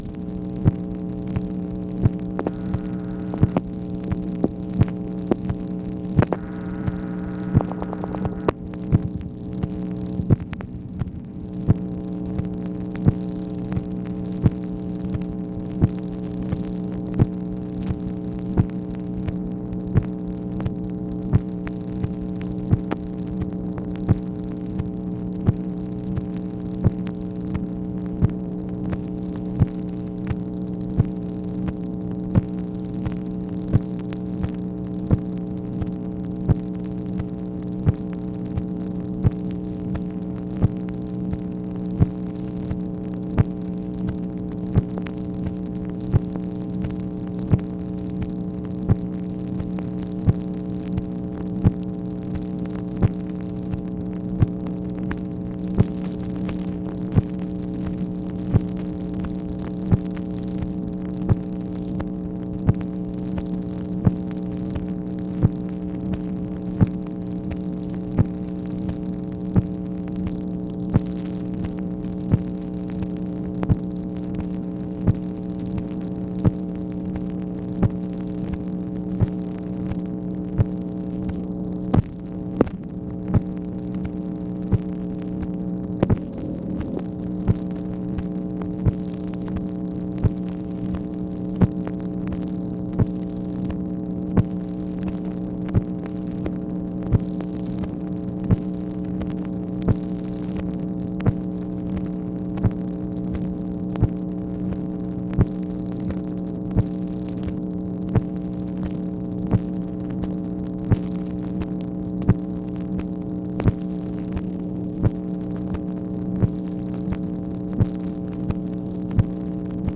MACHINE NOISE, May 7, 1965
Secret White House Tapes | Lyndon B. Johnson Presidency